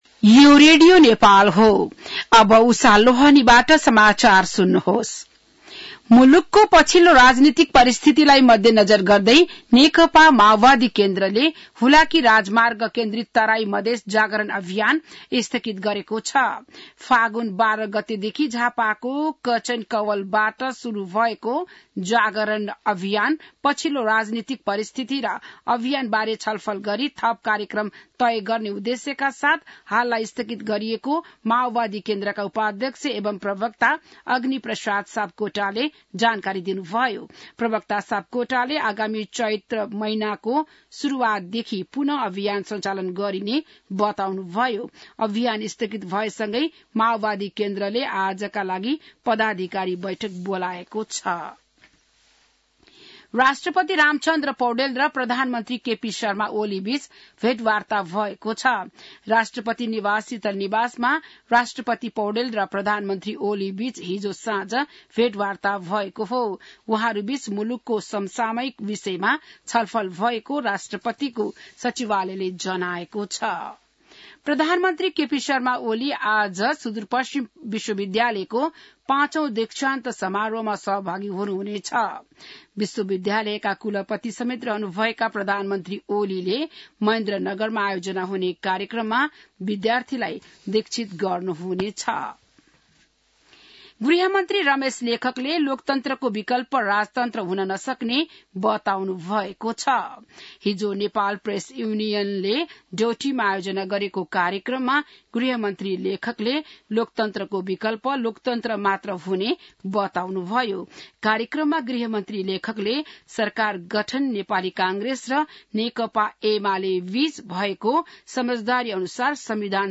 बिहान १० बजेको नेपाली समाचार : २७ फागुन , २०८१